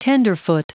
Prononciation du mot tenderfoot en anglais (fichier audio)
Prononciation du mot : tenderfoot